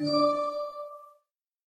whisper.ogg